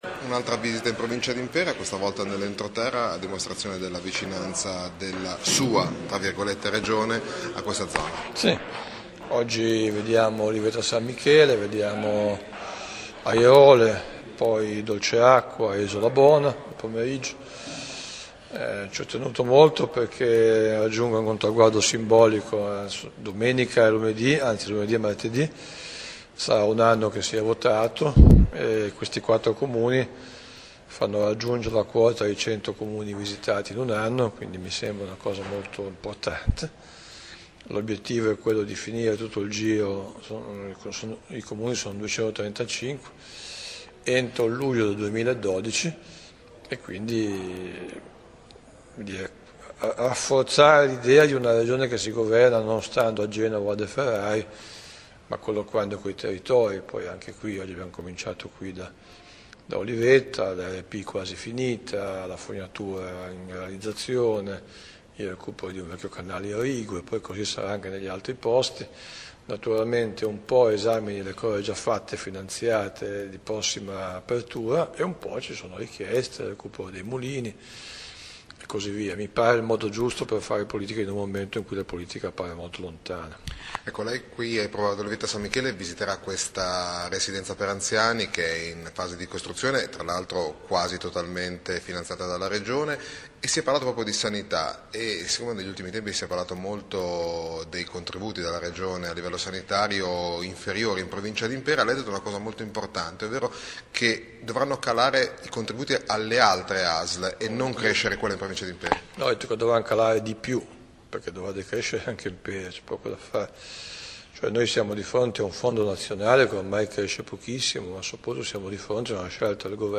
“Sono particolarmente contento – ha detto Burlando (l’audio dell’intervista cliccando